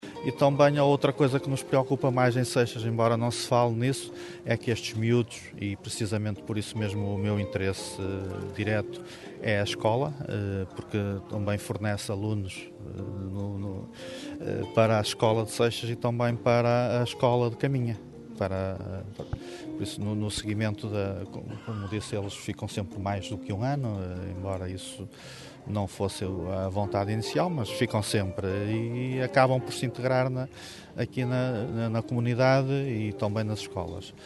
Cerca de meia centena de pessoas reuniram-se ontem ao final da tarde em Caminha para uma vigília a favor da manutenção do Centro de Acolhimento Temporário Benjamim (CAT) de Seixas, cujo encerramento por parte da APPACDM, está previsto para finais de junho.
Rui Ramalhosa lembrou também que o encerramento do CAT Benjamim pode complicar a manutenção da Escola Primária de Seixas que neste momento luta com falta de alunos para se manter aberta.